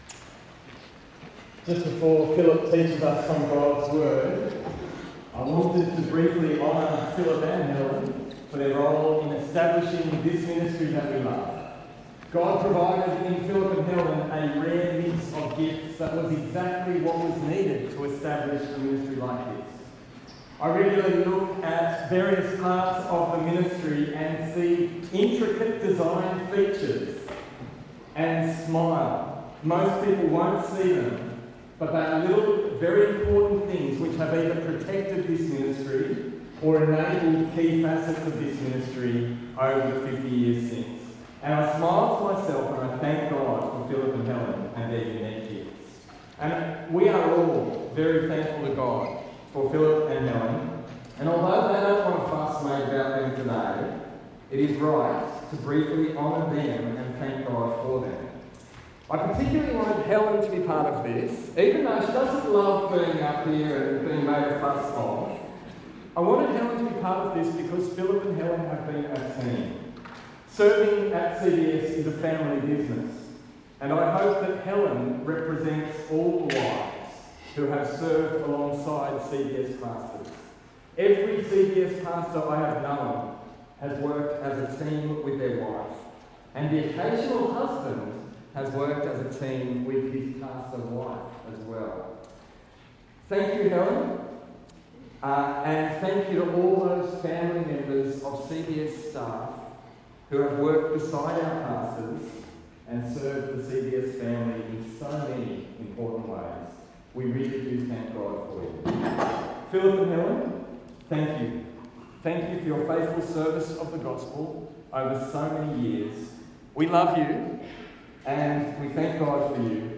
This talk was given at the 50th Anniversary of Campus Bible Study.